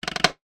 NOTIFICATION_Click_05_mono.wav